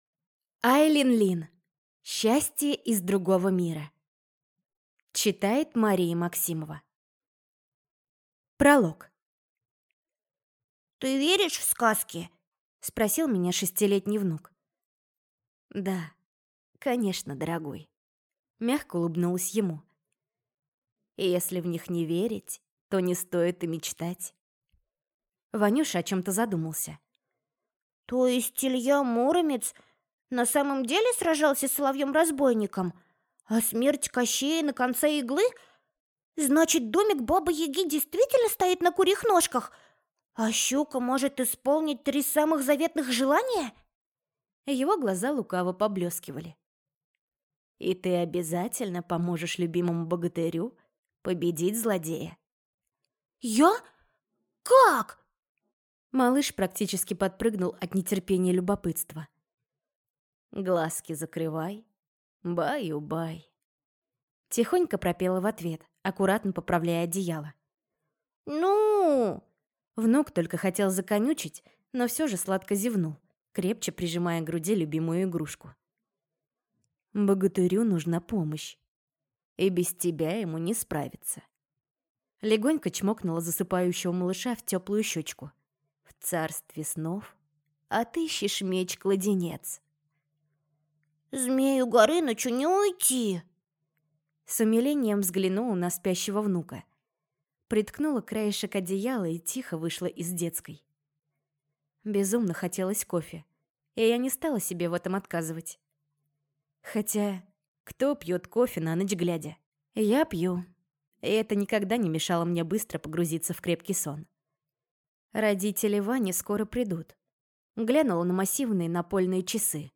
Счастье из другого мира (слушать аудиокнигу бесплатно) - автор Айлин Лин